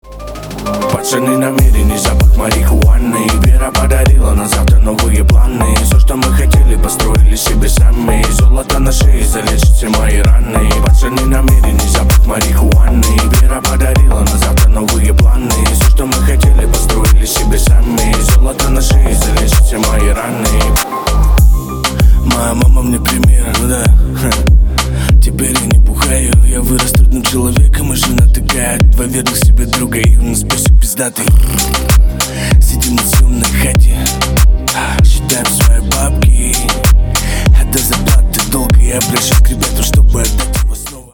• Качество: 320 kbps, Stereo
Рэп и Хип Хоп
громкие